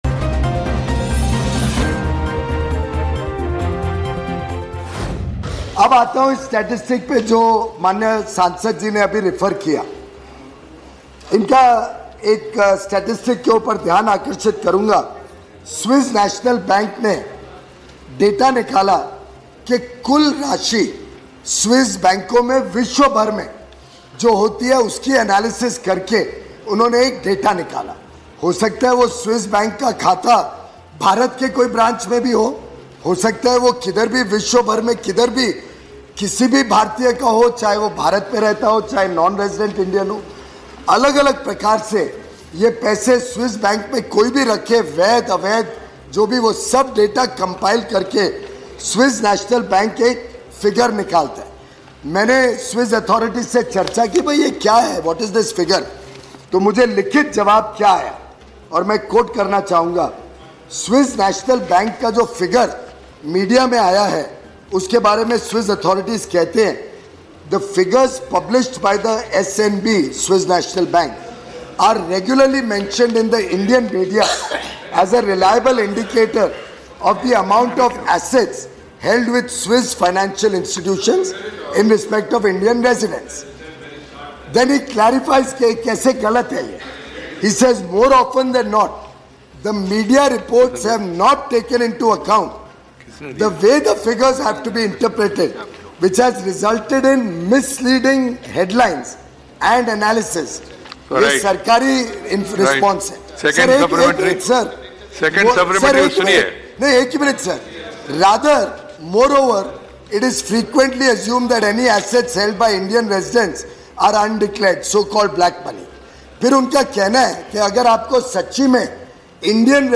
न्यूज़ रिपोर्ट - News Report Hindi / स्विस बैंकों में भारतीयों का पैसा 80 फीसदी घटा: पीयूष गोयल